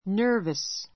nervous 中 A2 nə́ː r vəs ナ ～ヴァ ス 形容詞 ❶ 緊張 きんちょう して , どきどきして; びくびくして; 神経質な, 憶病 おくびょう な get nervous get nervous （試験・舞台 ぶたい などで）あがる I was a little nervous.